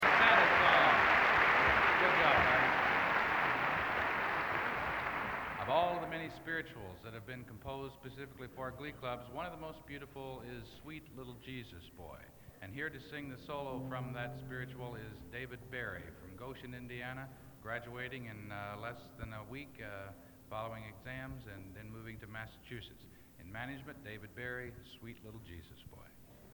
Collection: Christmas Show 1983
Genre: | Type: Christmas Show |